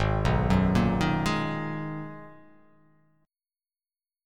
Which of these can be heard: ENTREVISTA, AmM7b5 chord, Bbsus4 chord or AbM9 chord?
AbM9 chord